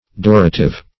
Durative \Dur"a*tive\, a.